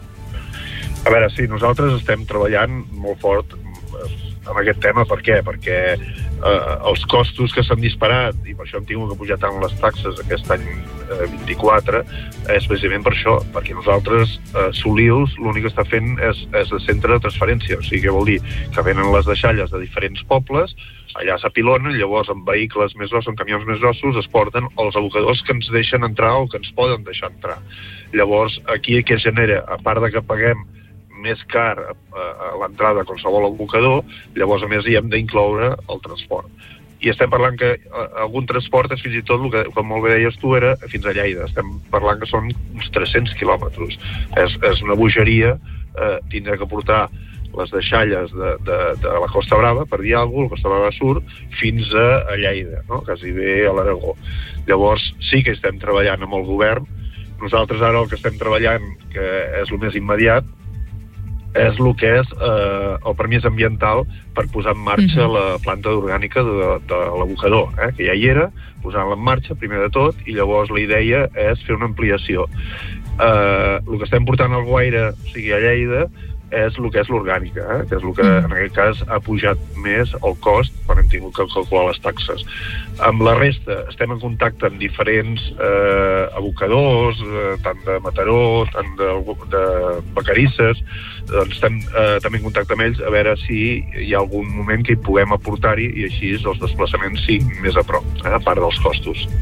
Entrevistes Supermatí
I per parlar de l’actualitat d’aquest procés de tancament i dels propers mesos del complex ens ha visitat al Supermatí el president del Consorci de Solius i alcalde de Llagostera, Narcís Llinàs.